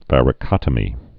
(vărĭ-kŏtə-mē)